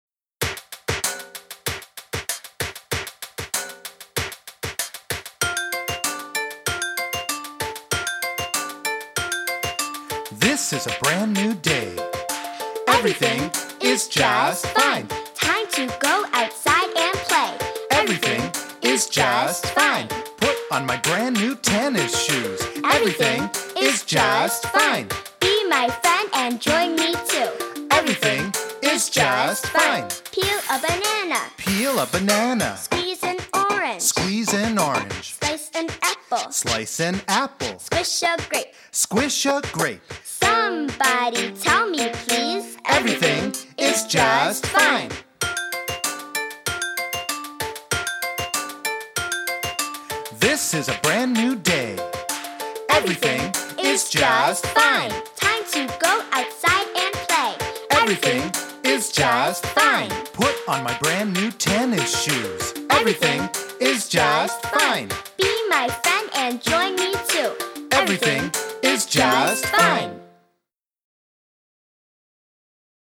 rhythmic chant
motivating chant